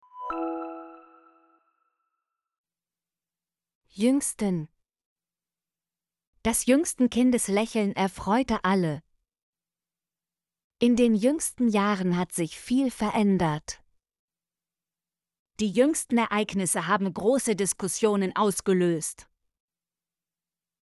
jüngsten - Example Sentences & Pronunciation, German Frequency List